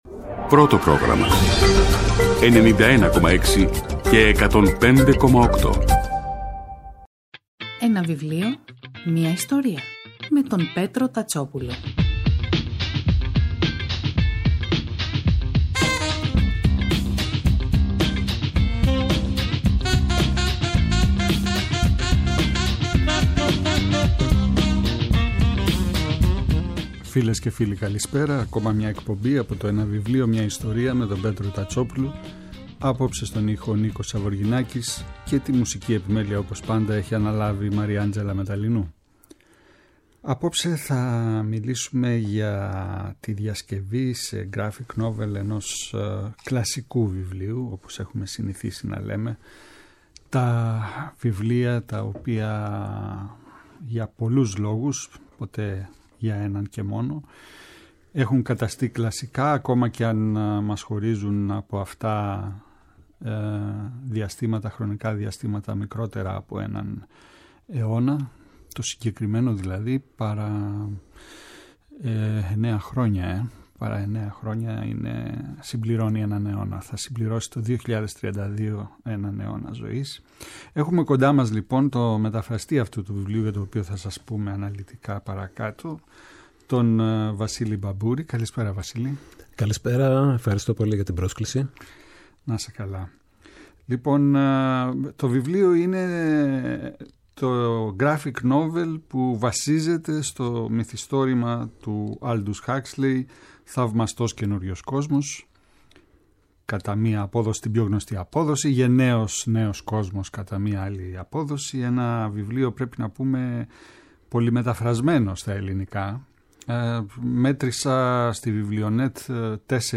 Κάθε Σάββατο και Κυριακή, στις 5 το απόγευμα στο Πρώτο Πρόγραμμα της Ελληνικής Ραδιοφωνίας ο Πέτρος Τατσόπουλος, παρουσιάζει ένα συγγραφικό έργο, με έμφαση στην τρέχουσα εκδοτική παραγωγή, αλλά και παλαιότερες εκδόσεις. Η γκάμα των ειδών ευρύτατη, από μυθιστορήματα και ιστορικά μυθιστορήματα, μέχρι βιογραφίες, αυτοβιογραφίες και δοκίμια.